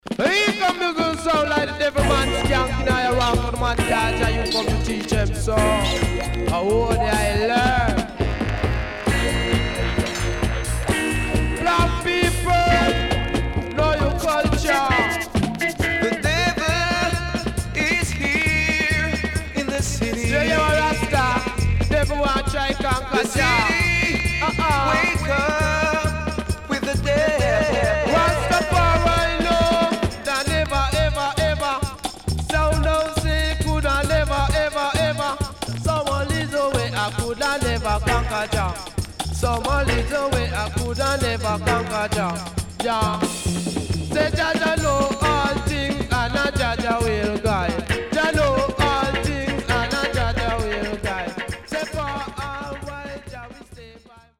SIDE A:全体的にチリノイズがあり、少しプチノイズ入ります。